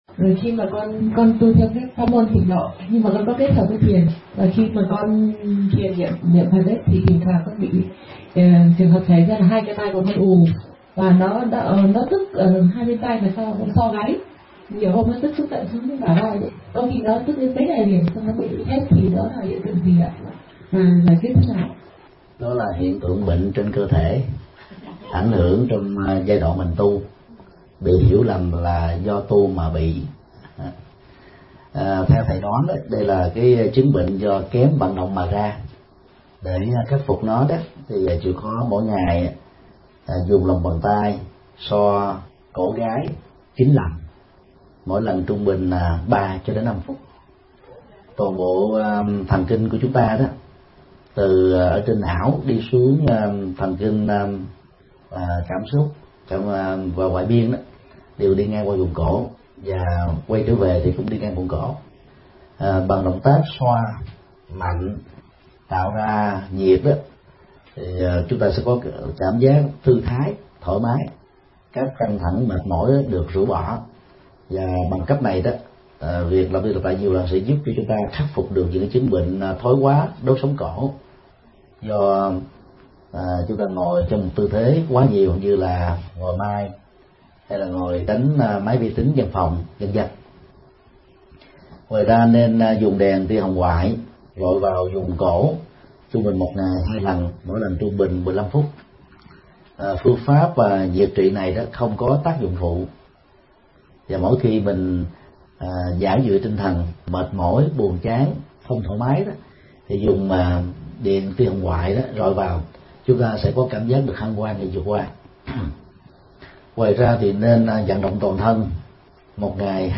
Vấn đáp: Hướng dẫn thực tập buông xã – Thích Nhật Từ